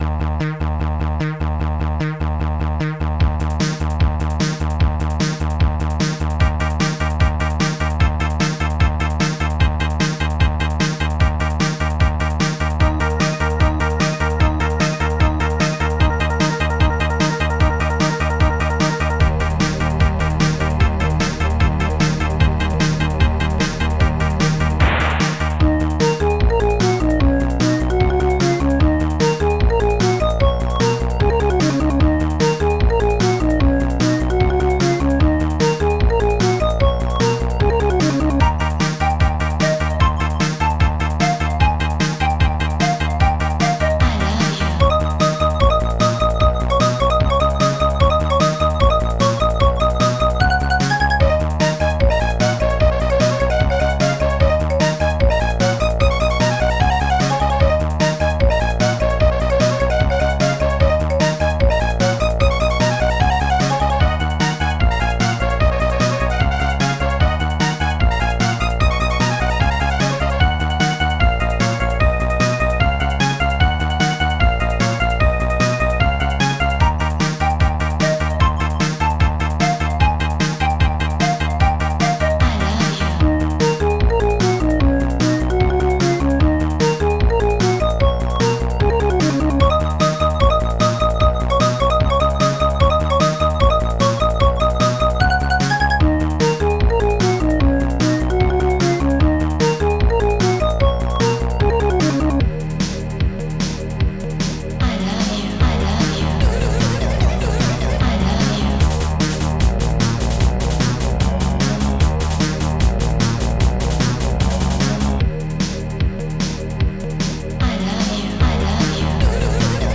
st-01:bassdrum4
st-01:hihat2
st-01:marimba
st-02:dumpfbass
st-03:glasssnare